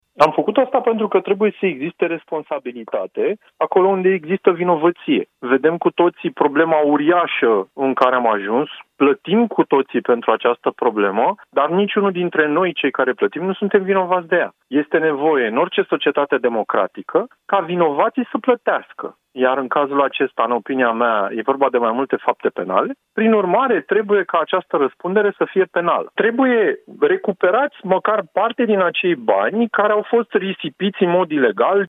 Liderul partidului DREPT, Vlad Gheorghe: „În cazul acesta, în opinia mea, e vorba de mai multe fapte penale”